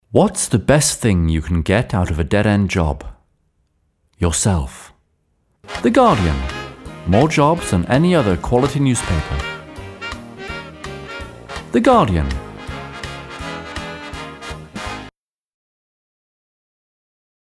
voix anglaise, comédien pro britannique, beaucoup d'expérience de voix off
britisch
Sprechprobe: eLearning (Muttersprache):
British actor with lots of experience of voice work and theatre